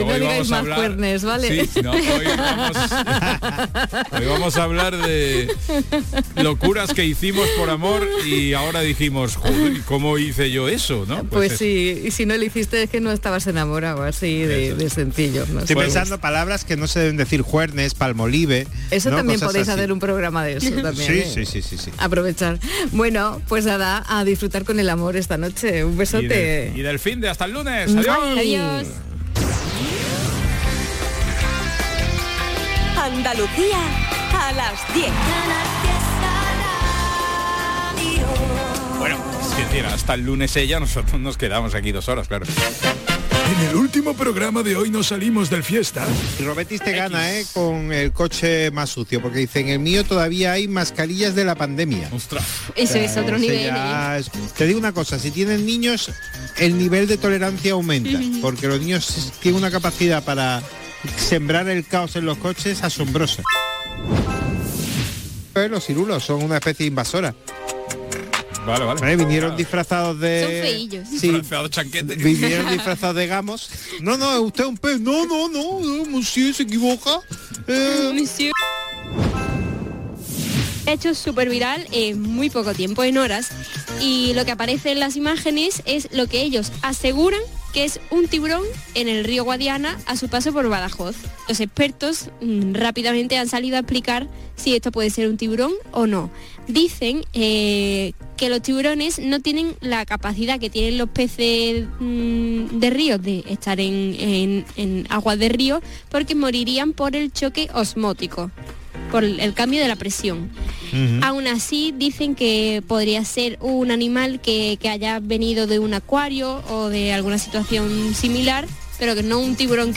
Buena música, humor y alguna que otra reflexión para organizarte tus cosas. Canal Fiesta te ofrece un programa nocturno de noticias y curiosidades muy loco. Un late radio show para que te quedes escuchando la radio hasta que te vayas a dormir.